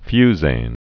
(fyzān, fy-zān)